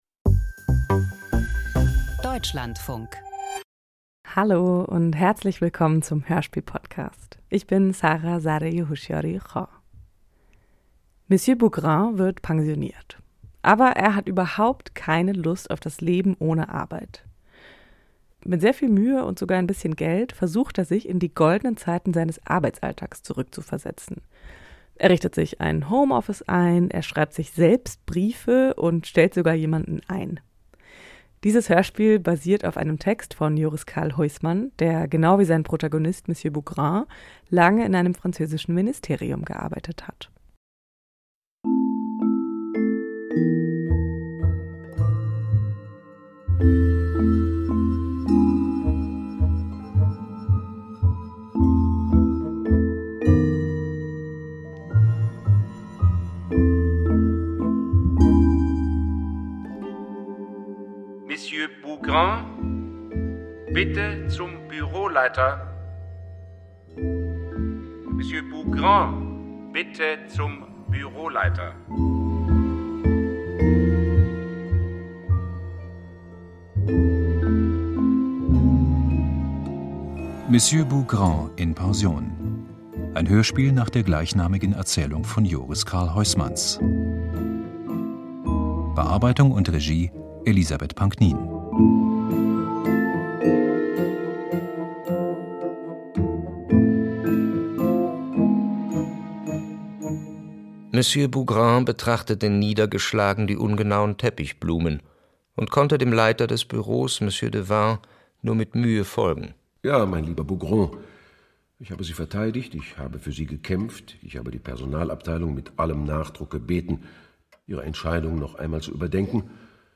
Nach einer Novelle von Joris Karl Huysmans Aus dem Podcast Hörspiel